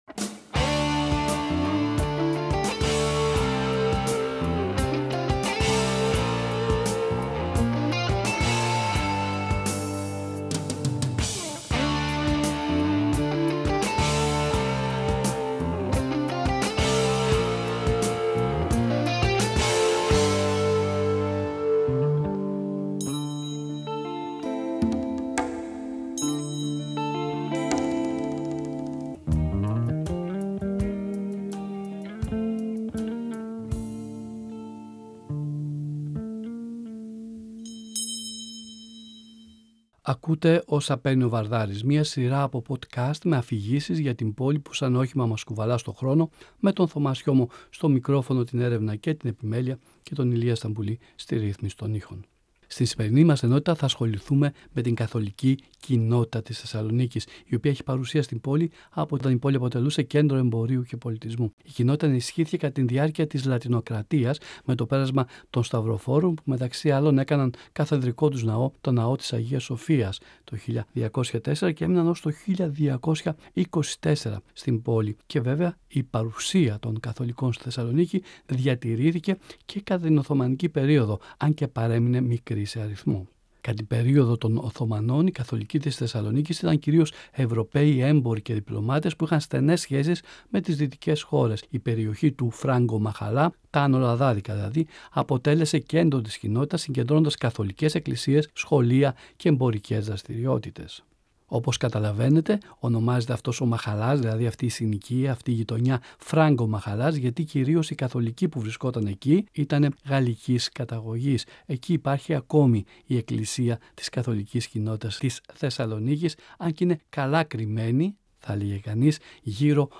Αφηγήσεις, ιστοριες, μικρές και μεγάλες λεπτομέρειες για την πόλη που σαν όχημα μας κουβαλά στον χρόνο.